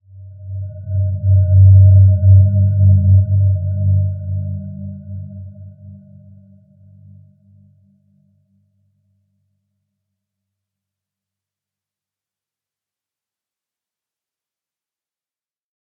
Dreamy-Fifths-G2-mf.wav